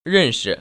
[rèn‧shi] 런스  ▶